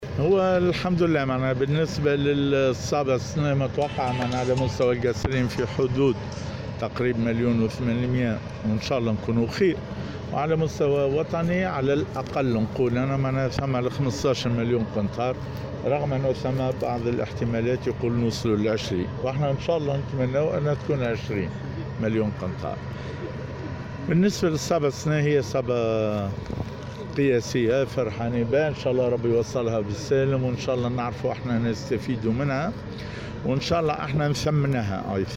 و في السياق ذاته أفاد رئيس الاتحاد التونسي للفلاحة و الصيد البحري عبد المجيد الزار في تصريح خصّ به إذاعة السيليوم أف أم  أنّ الصابة المتوقعة هذا الموسم على مستوى ولاية القصرين مليون قنطار و 800 ،  أما على مستوى وطني فهي تقارب 20 مليون قنطار .